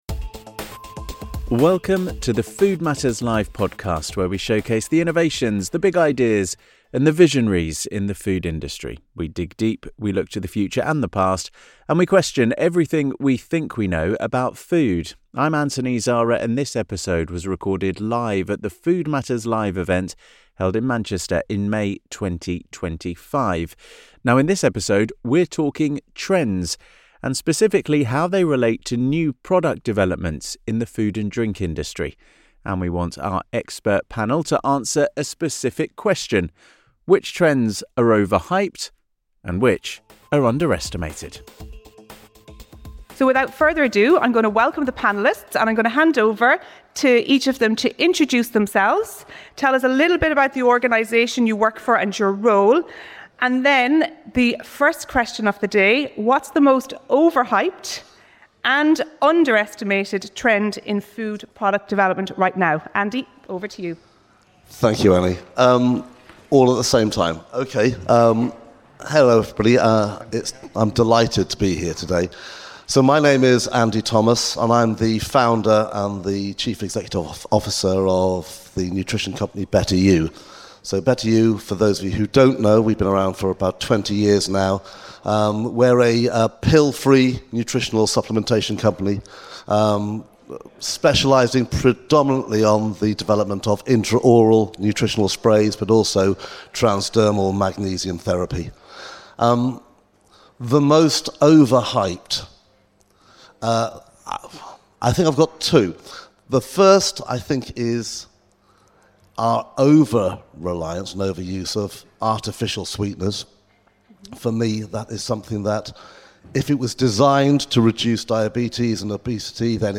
In this episode of the Food Matters Live podcast, recorded at our event in Manchester in May 2025, we gather a panel of industry experts to discuss what's overhyped and underestimated in NPD right now.